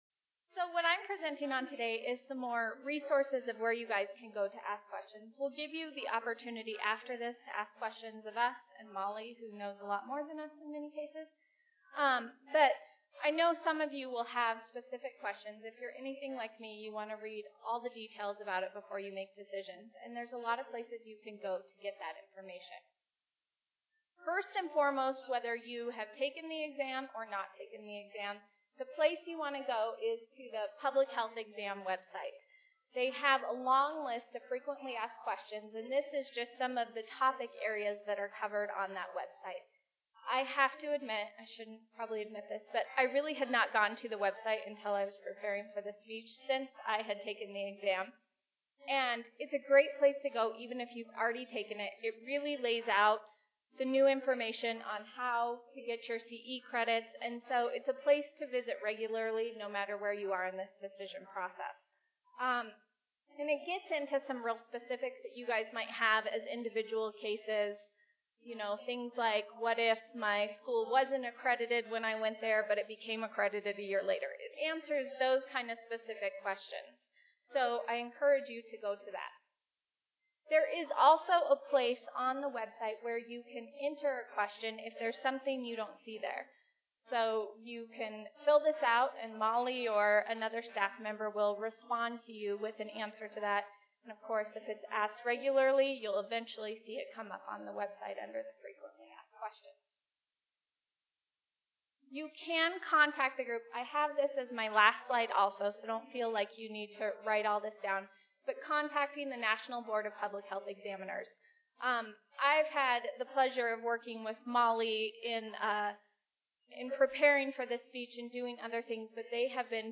This panel of CPH professionals will share their experiences with the CPH process thus far.
Learners are encouraged to ask questions of the panel, all CPH professionals.